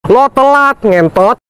Kategori: Suara viral
Keterangan: Download efek suara meme 'Lo Telat, Ngentot' viral di media sosial, sering digunakan dalam video lucu.